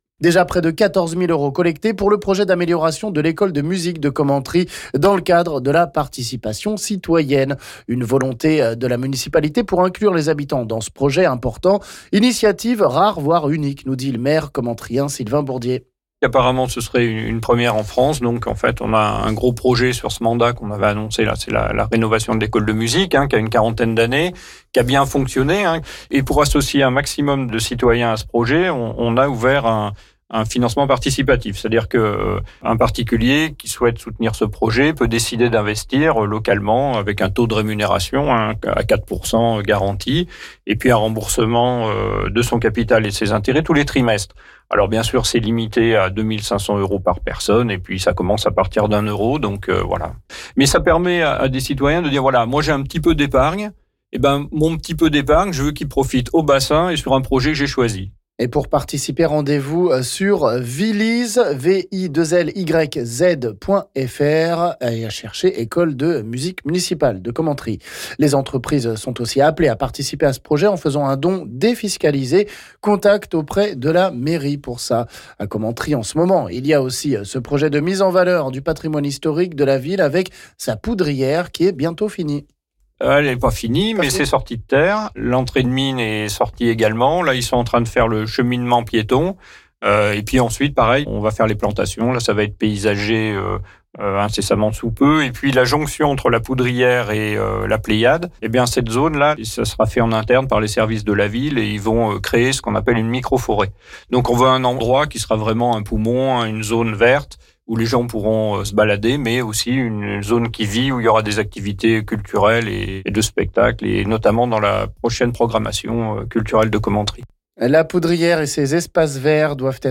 On fait le point sur ces 2 projets avec le maire commentryen Sylvain Bourdier...